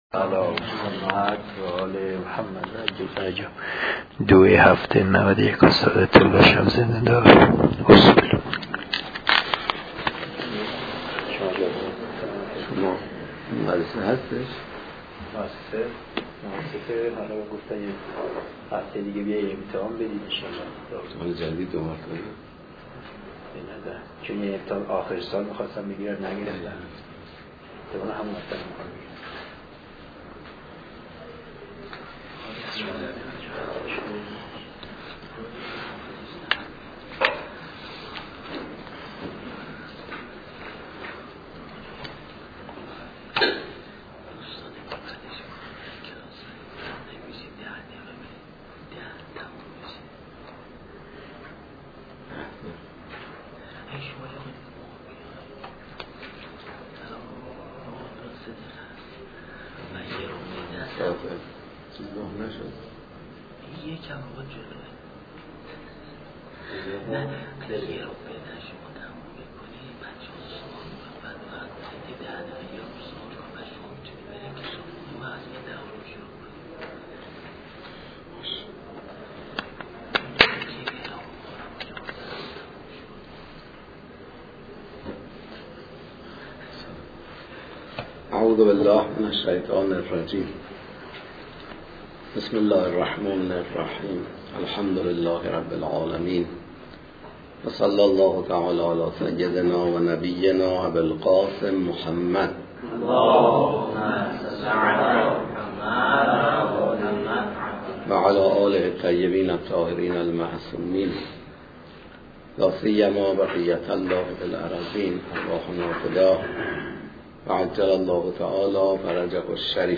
پخش آنلاین درس